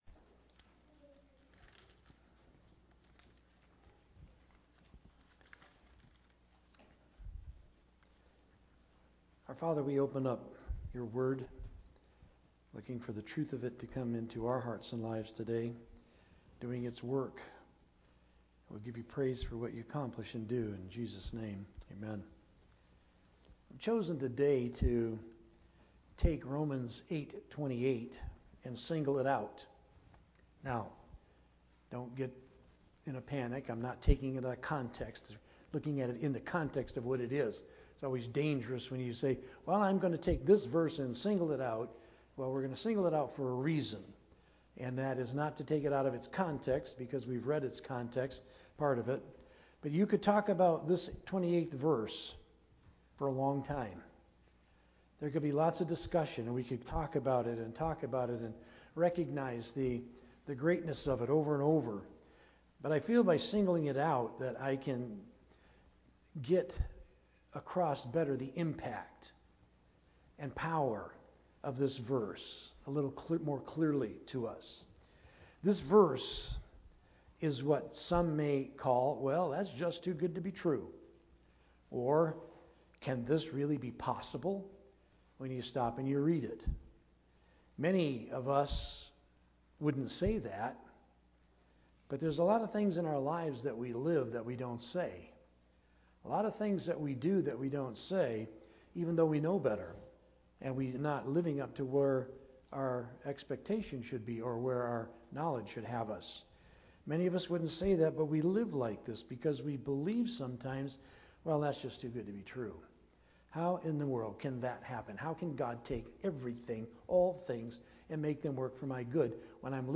From Series: "Sunday Morning - 11:00"
Related Topics: Sermon